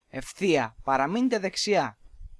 (male)